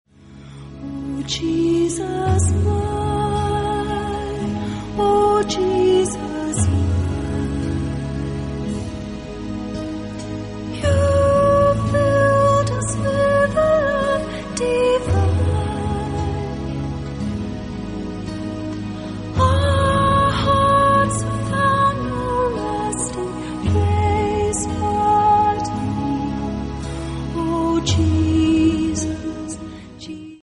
erstklassigen Live-Aufnahme
• Sachgebiet: Praise & Worship